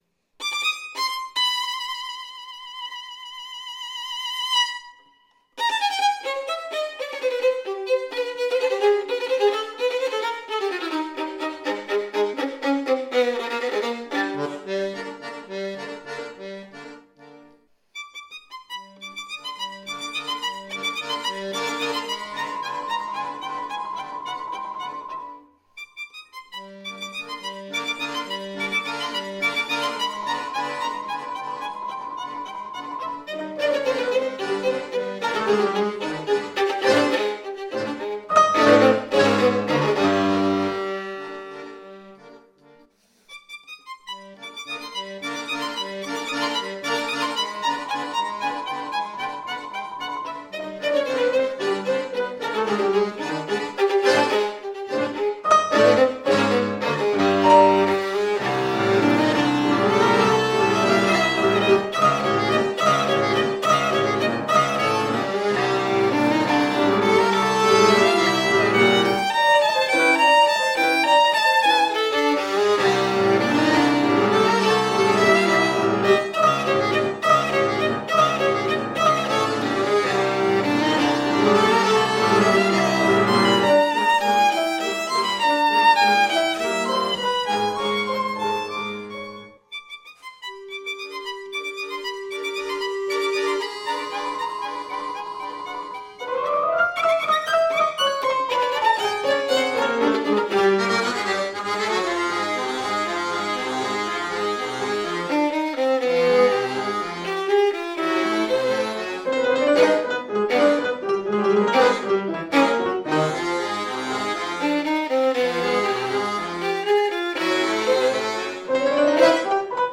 Violino, Fisarmonica e Pianoforte